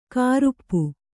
♪ kāṛuppu